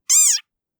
oscarpilot/selfdrive/frogpilot/assets/custom_themes/stock_theme/sounds/disengage.wav
disengage.wav